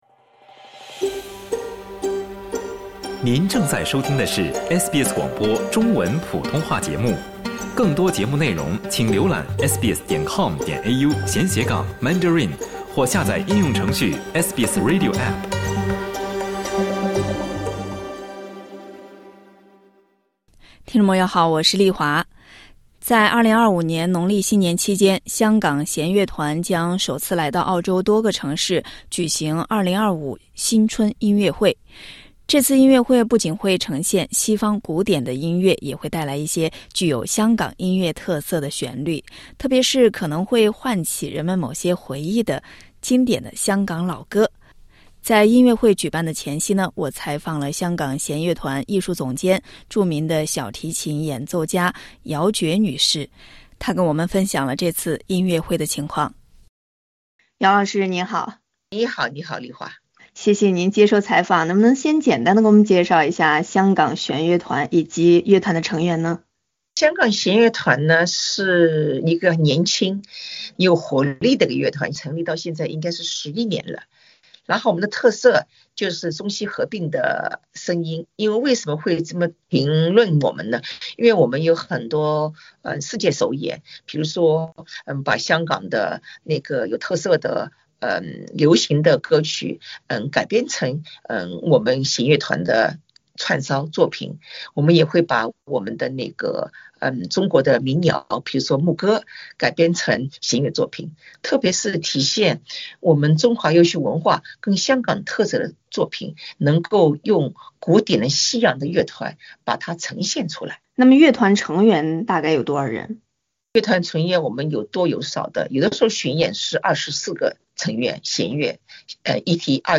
香港弦乐团艺术总监、著名小提琴演奏家姚珏女士和SBS普通话节目分享这次音乐会的信息。点击音频收听采访。